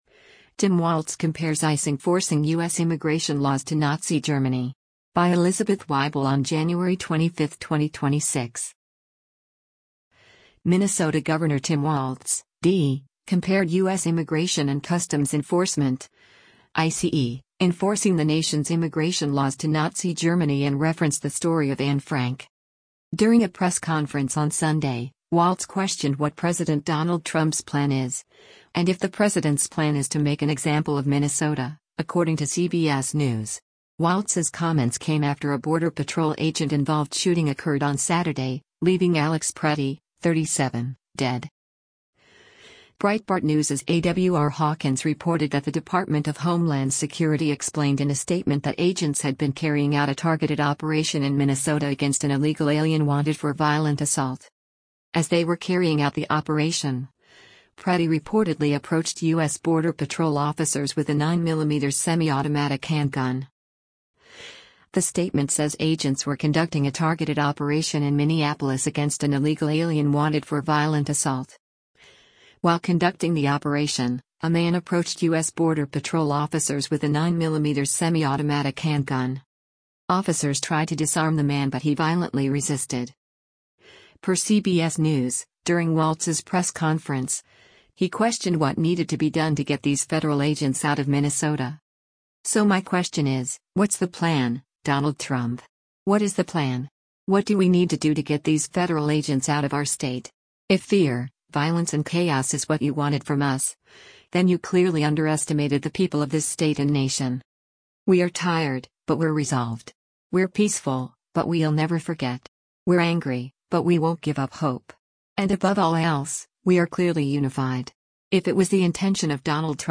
During a press conference on Sunday, Walz questioned what President Donald Trump’s “plan” is, and if the president’s plan is to “make an example of Minnesota,” according to CBS News.